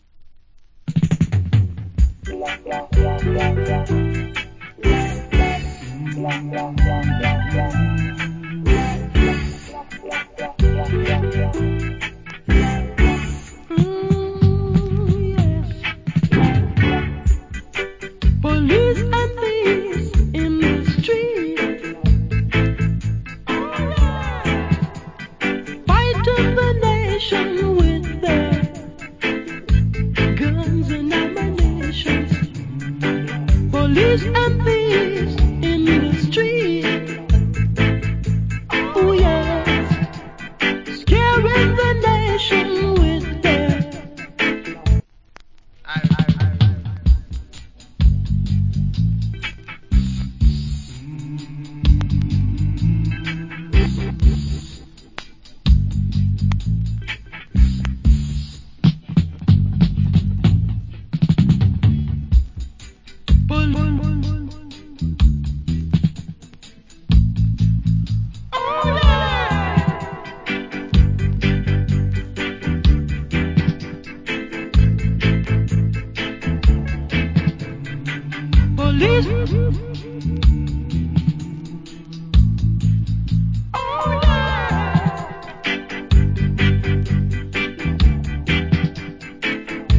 Roots Rock Vocal.
/ Nice Dub.